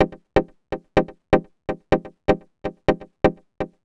cch_synth_pluck_wet_125_Fm.wav